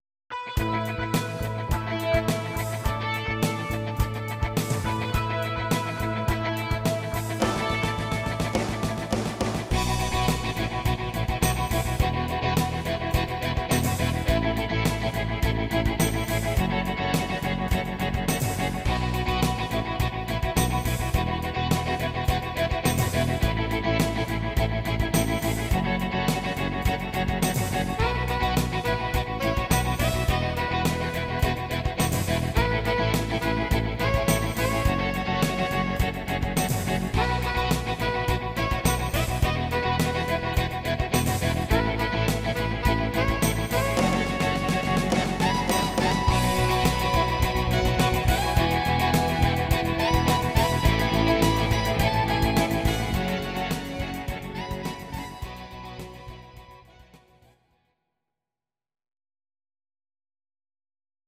These are MP3 versions of our MIDI file catalogue.
Please note: no vocals and no karaoke included.
Your-Mix: Rock (2958)